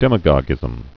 (dĕmə-gôgĭz-əm, -gŏgĭz-)